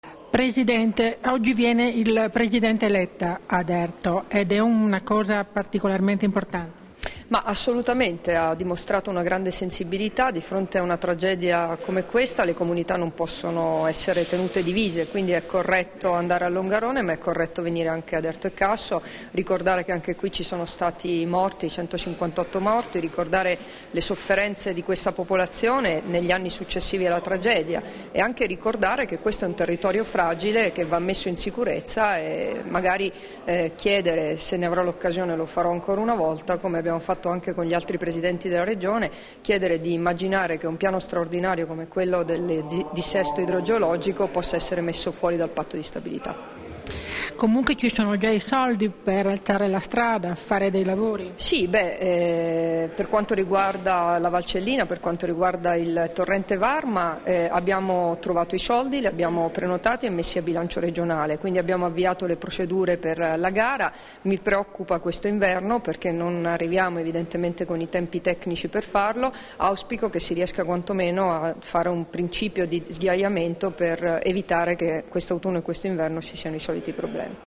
Dichiarazioni di Debora Serracchiani (Formato MP3) [1241KB]
sulla visita del presidente del Consiglio dei Ministri Enrico Letta a Erto e Casso, rilasciate a Erto il 12 ottobre 2013